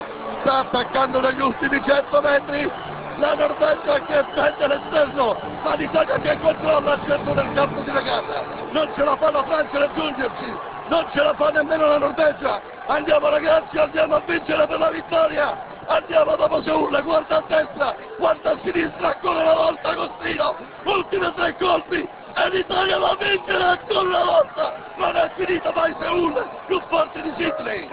Telecronaca Galeazzi - A. Abbagnale - Tizzano - ORO 2 di COPPIA